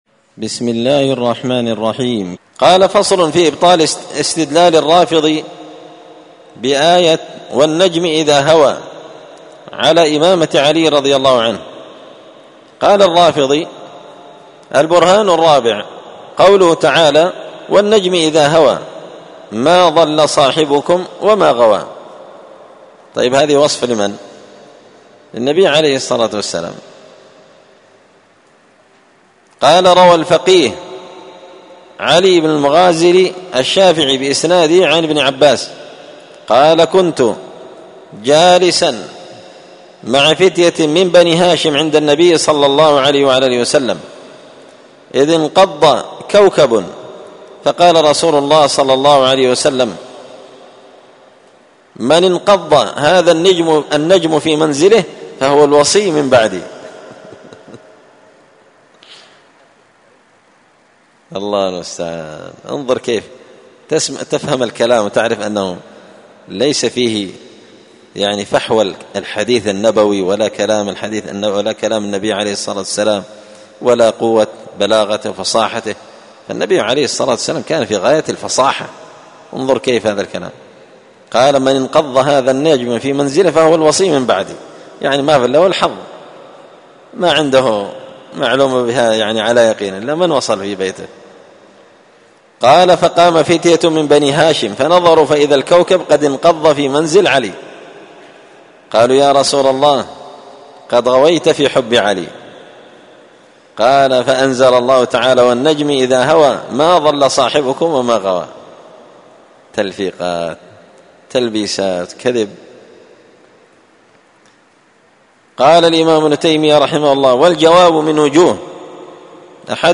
الأربعاء 22 محرم 1445 هــــ | الدروس، دروس الردود، مختصر منهاج السنة النبوية لشيخ الإسلام ابن تيمية | شارك بتعليقك | 68 المشاهدات